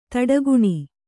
♪ taḍaguṇi